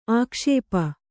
♪ ākṣēpa